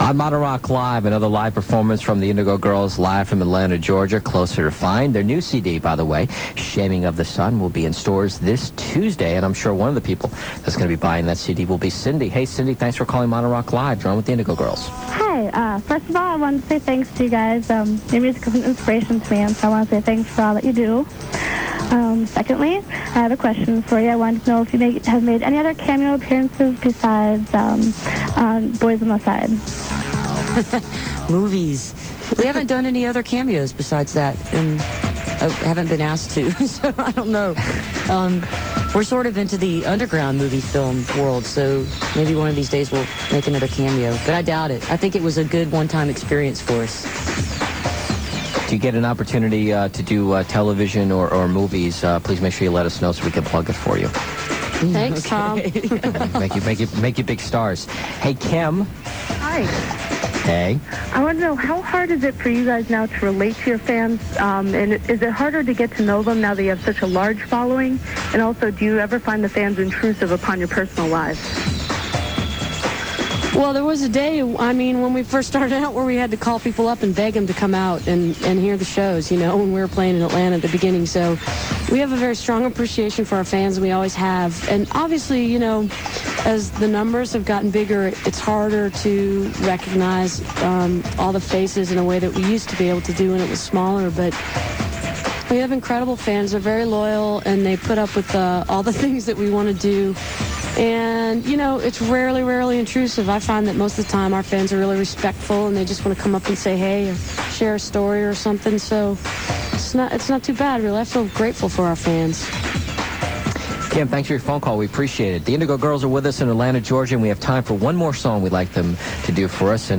10. interview (2:07)